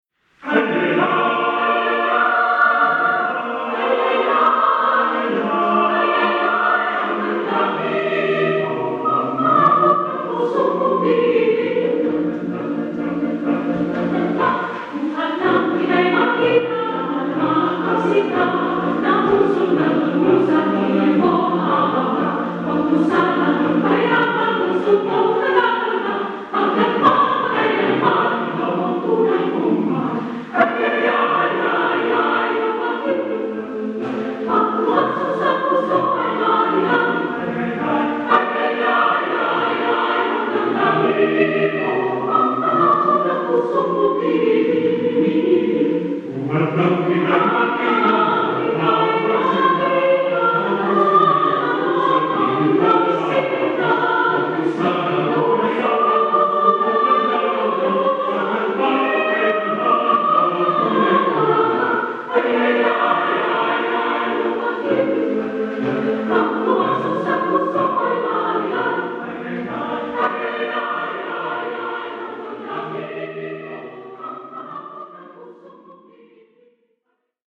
Voicing: SSAATTBB a cappella